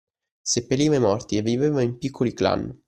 Read more (invariable, masculine) clan (invariable, masculine) team (invariable, masculine) gang Frequency C1 Hyphenated as clàn Pronounced as (IPA) /ˈklan/ Etymology Unadapted borrowing from English clan.